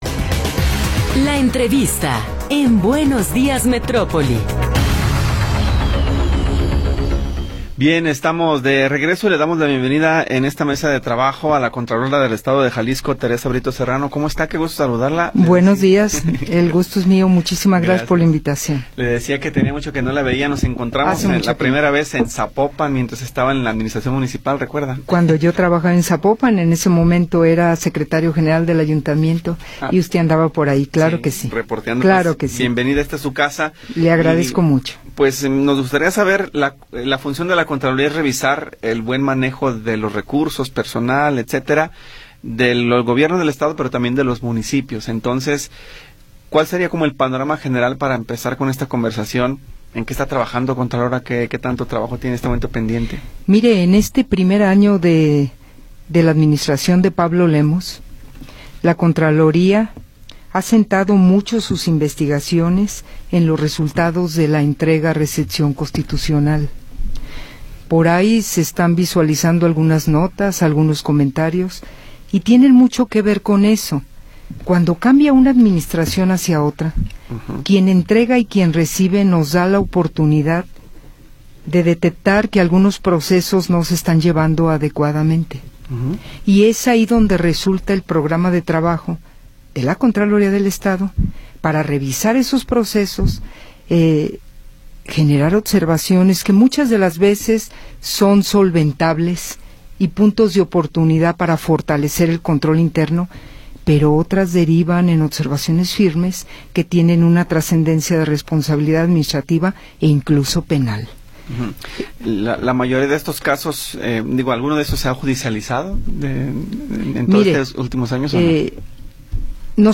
Entrevista con Teresa Brito Serrano